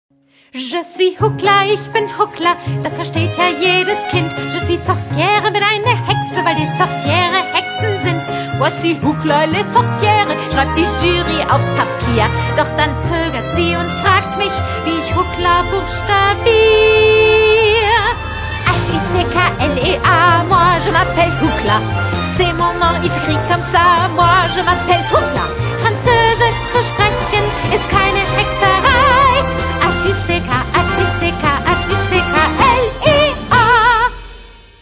eine lustige Geschichte mit 6 tollen Songs
Kinderlieder • Französisch